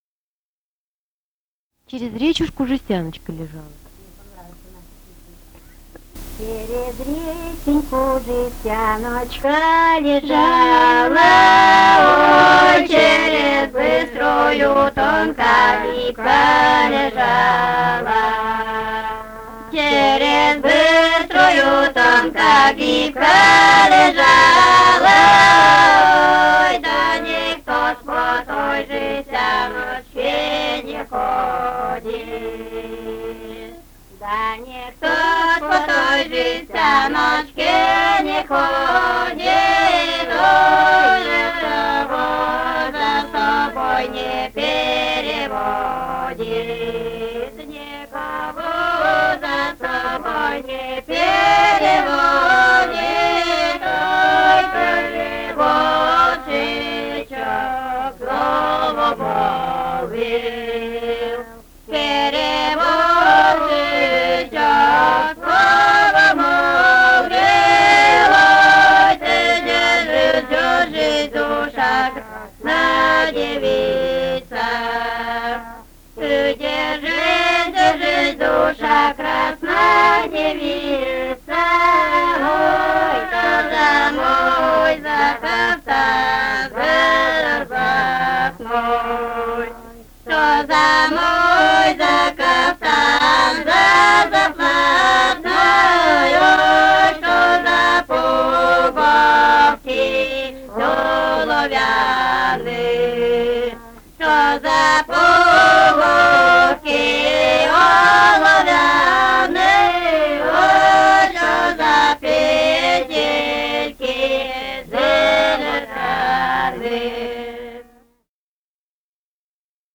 Этномузыкологические исследования и полевые материалы
Пермский край, д. Пахомово Очёрского района, 1968 г. И1073-13